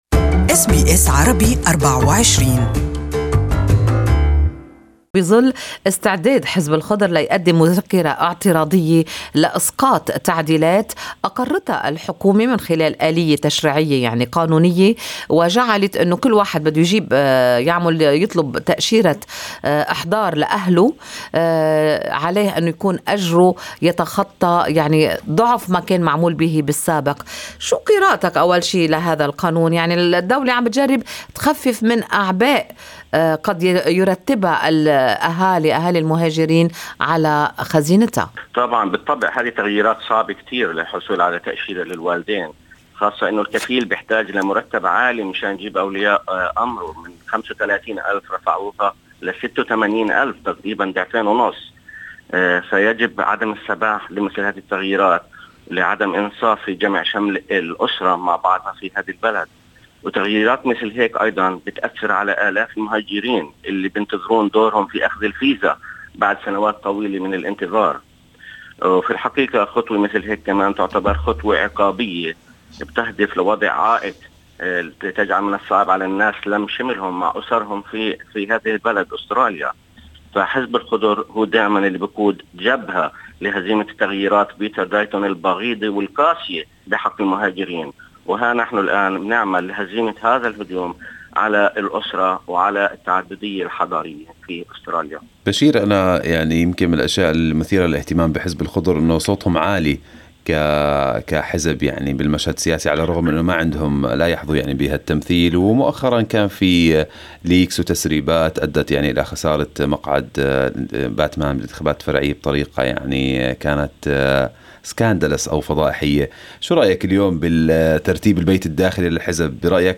المقابلة كاملة مرفقة بالصورة أعلاه.